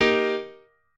piano8_30.ogg